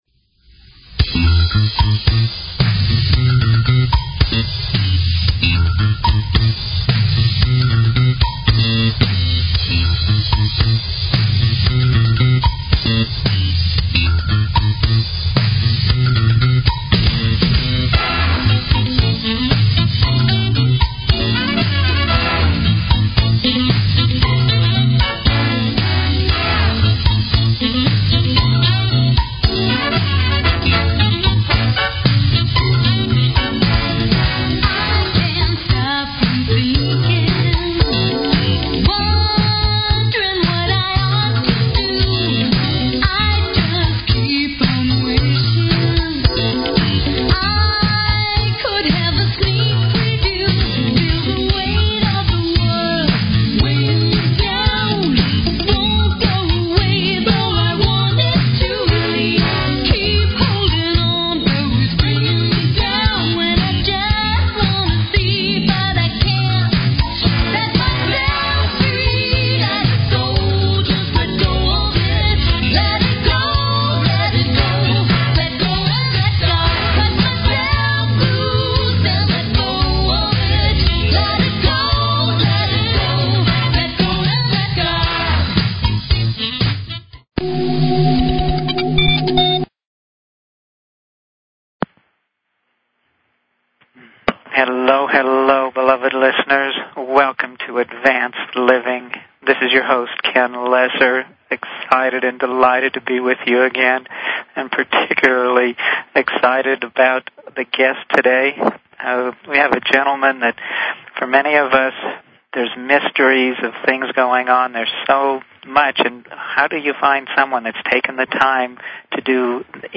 Talk Show Episode, Audio Podcast, Advanced_Living and Courtesy of BBS Radio on , show guests , about , categorized as